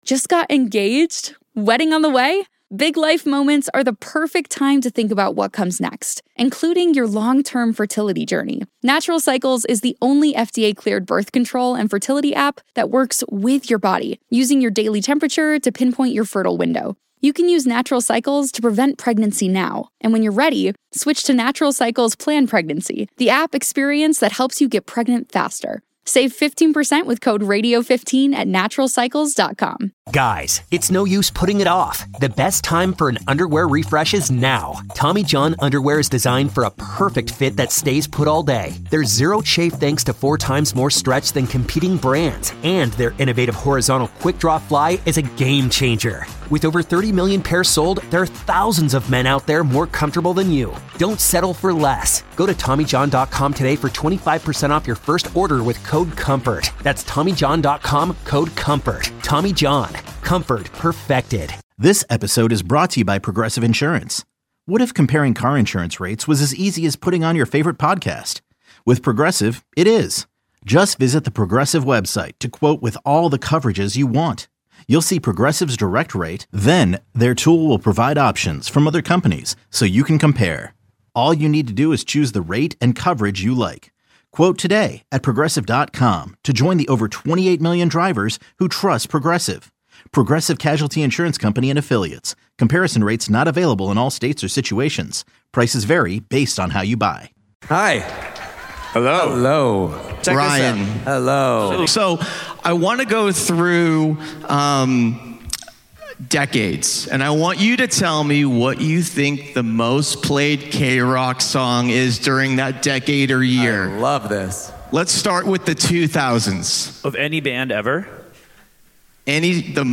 Listen to the world's biggest artists in conversation with KROQ hosts, recorded live every month in the DTS Sound Space at KROQ!